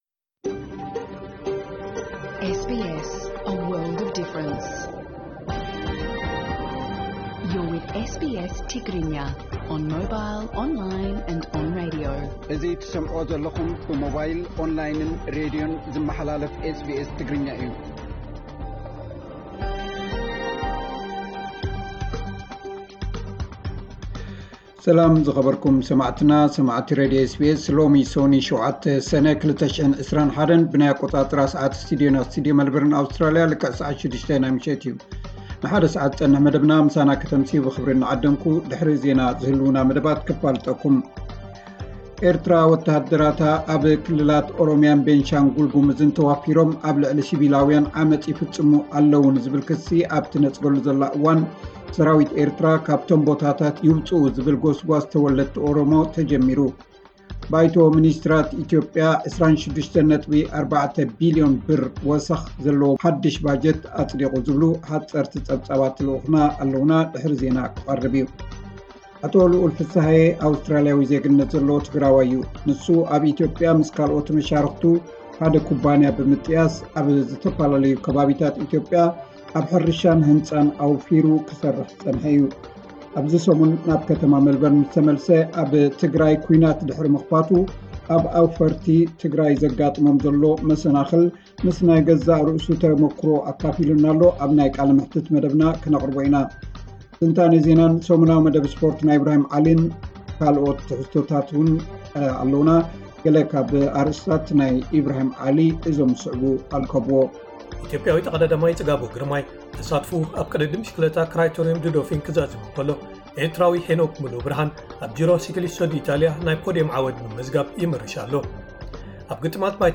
ዕለታዊ ዜና 7 ሰነ 2021 SBS ትግርኛ